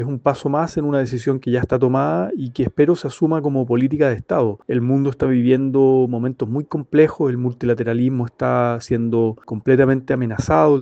El senador del Frente Amplio (FA), Juan Ignacio Latorre, sostuvo que, en un contexto global marcado por crecientes amenazas al multilateralismo, Michelle Bachelet representa una carta idónea para liderar las Naciones Unidas.